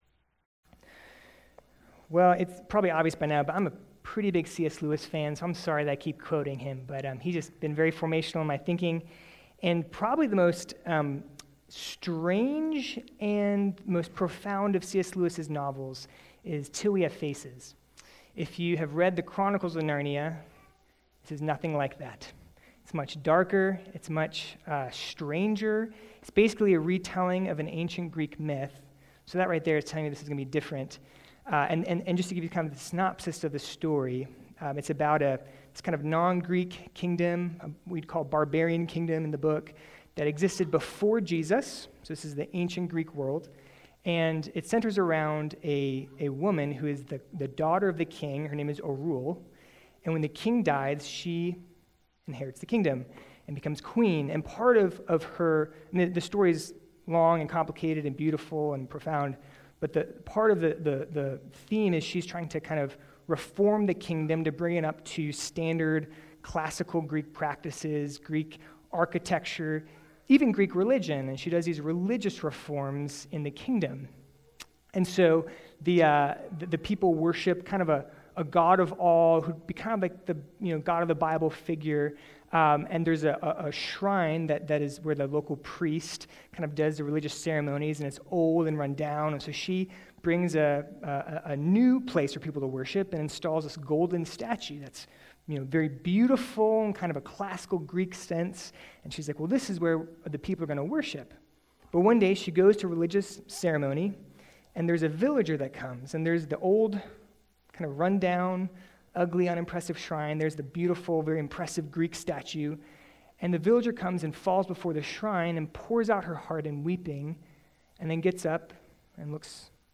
Morning Worship
Full Service August 30 Sermon Audio Bible References Luke 7:11